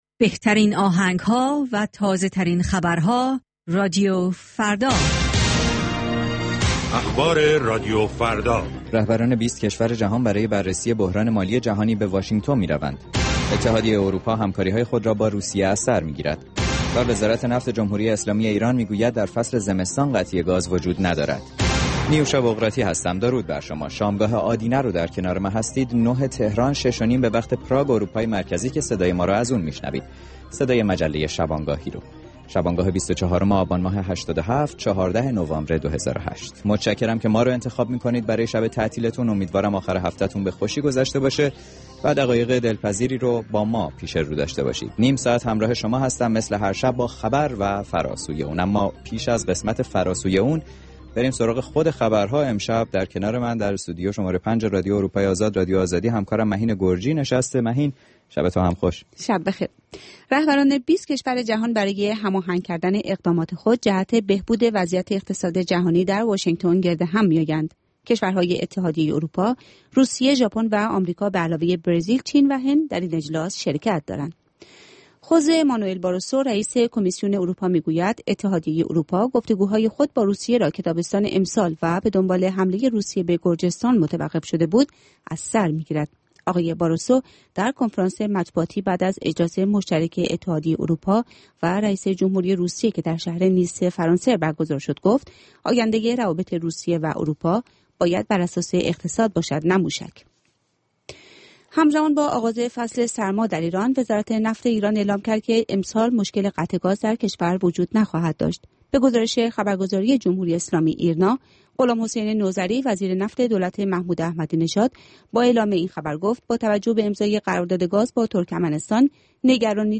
نیم ساعت با تازه ترین خبرها، گزارشهای دست اول در باره آخرین تحولات جهان و ایران از گزارشگران رادیوفردا در چهارگوشه جهان، گفتگوهای اختصاصی با چهره های خبرساز و کارشناسان، و مطالب شنیدنی از دنیای سیاست، اقتصاد، فرهنگ، دانش و ورزش.